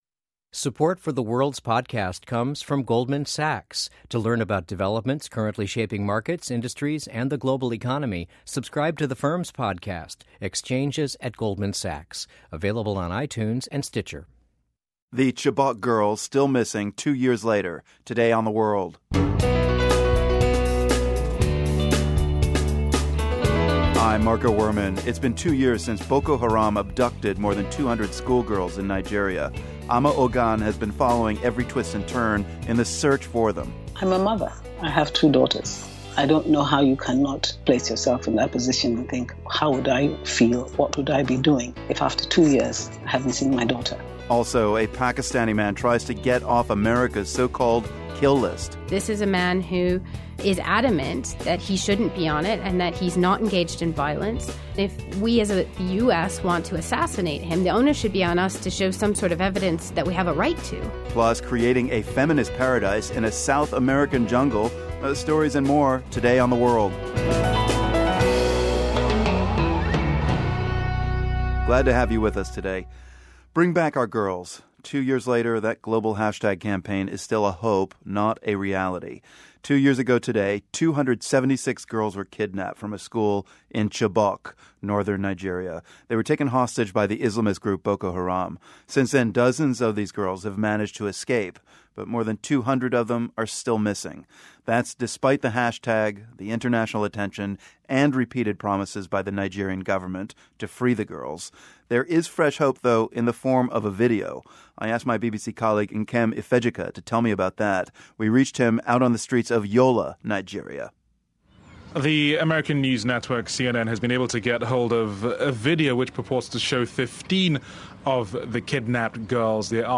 Now, a video has surfaced that seems to show some of the girls alive and well. Plus, we have a conversation with actress Milana Vayntrub.